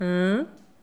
hein-etonnement_03.wav